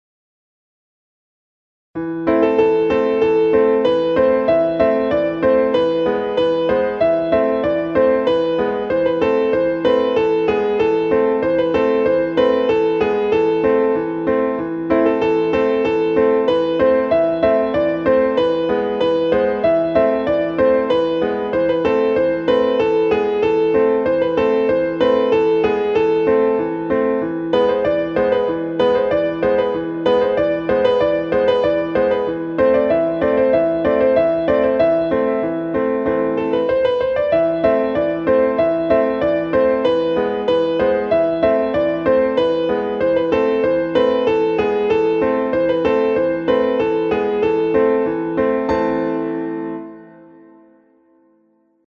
• حفظ ساختار ملودیک و جمله‌بندی محلی
🎧 فایل صوتی MP3 اجرای مرجع
• اجرای دقیق با تمپوی متعادل
• کمک به درک حس روایت‌گونه و فضای بومی قطعه
ایرانی